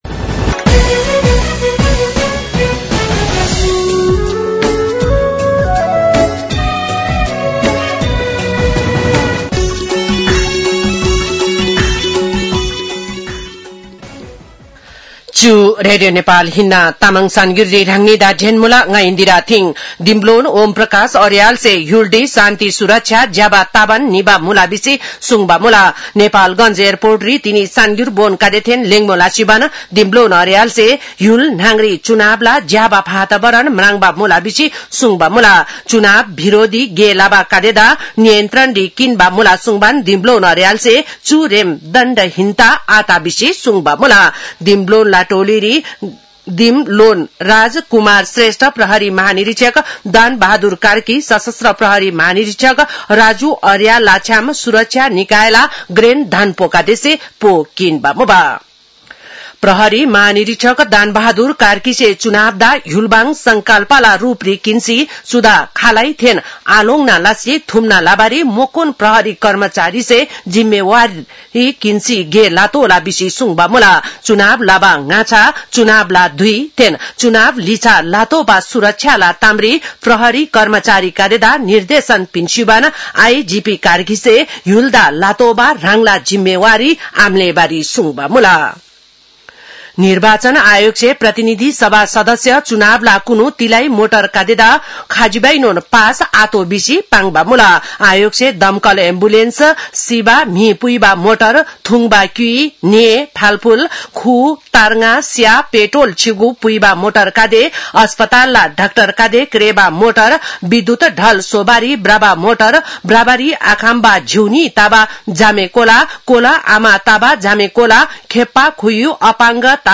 तामाङ भाषाको समाचार : १७ फागुन , २०८२